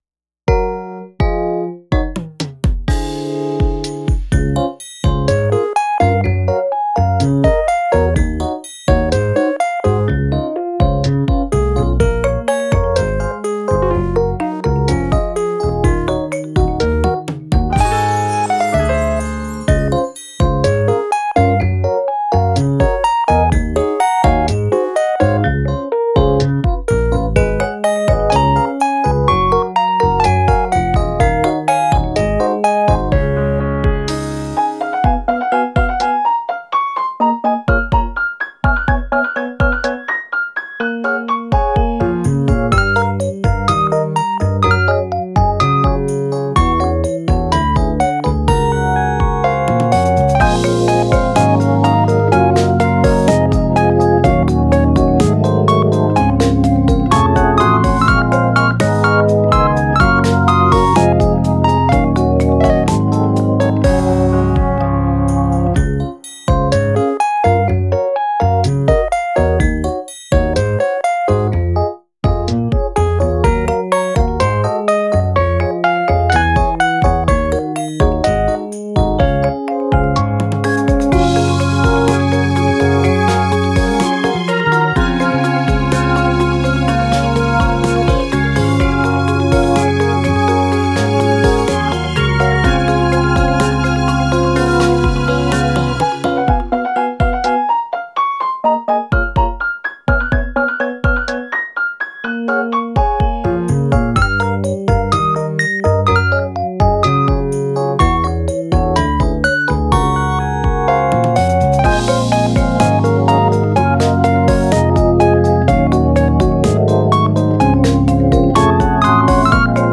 / インスト